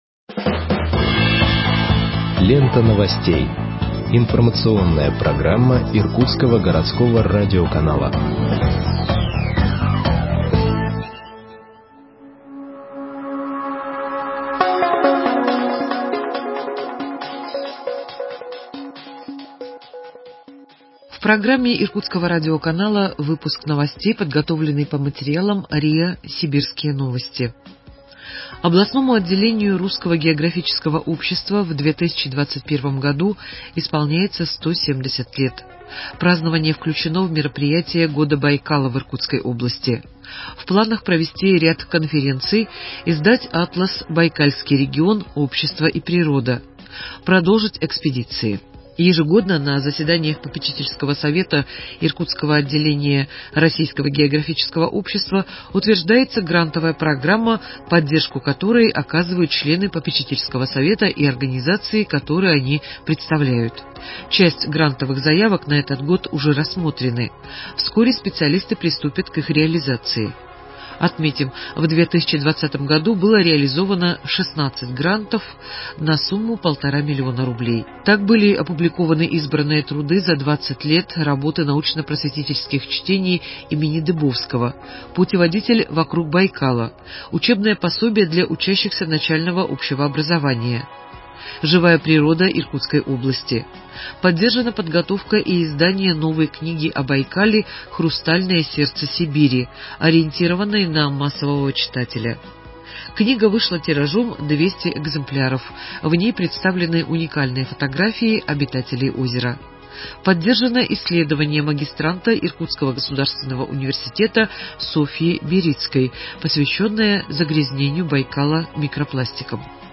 Выпуск новостей в подкастах газеты Иркутск от 20.01.2021 № 2